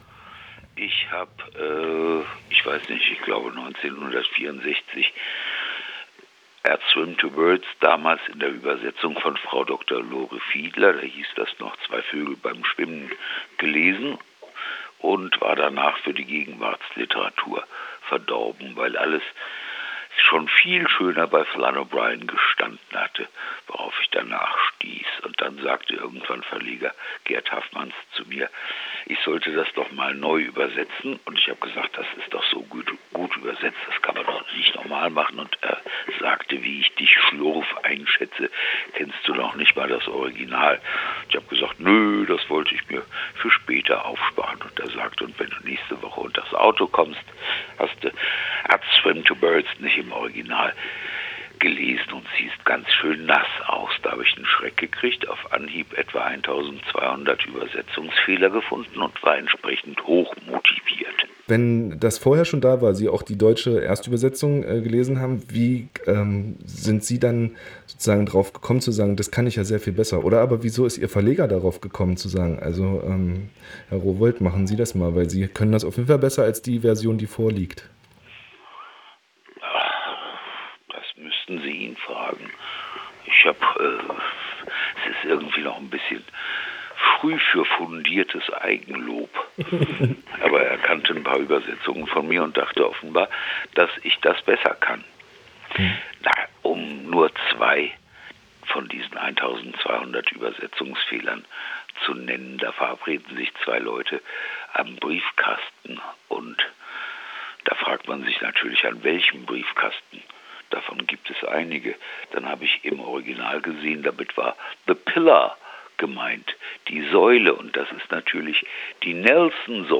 Ich habe mit dem Übersetzer Harry Rowohlt über O’Brien gesprochen. Und natürlich über das Übertragen von O’Briens Literatur ins Deutsche.